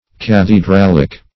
Cathedralic \Cath`e*dral"ic\, a.